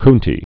(kntē)